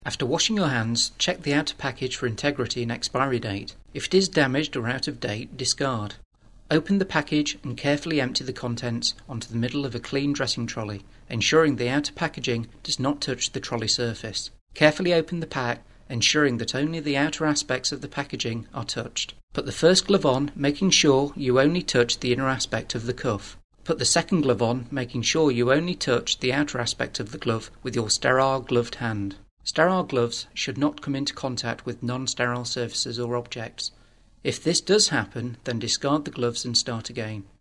Learning Objects : Lifelong Application Glove Use Narration audio (MP4) Narration audio (OGG) Contents Why use gloves?